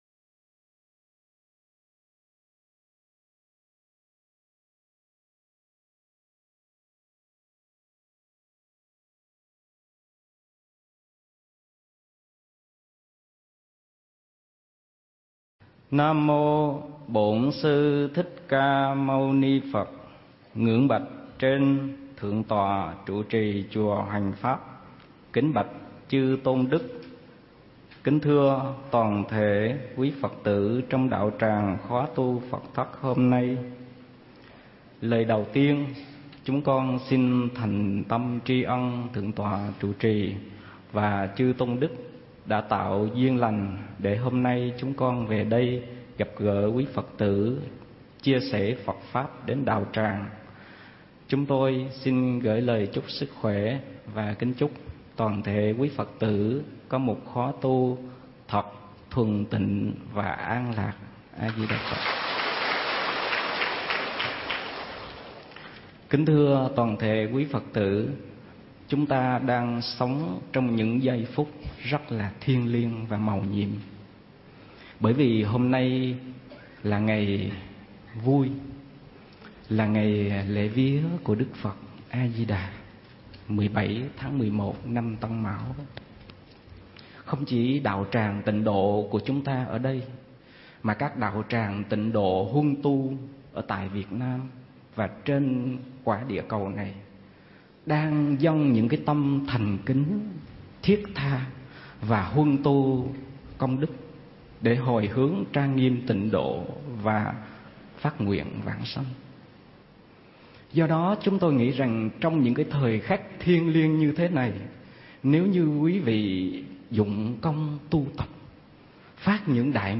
Nghe Mp3 thuyết pháp Đới Nghiệp Vãng Sanh
giảng tại chùa Hoằng Pháp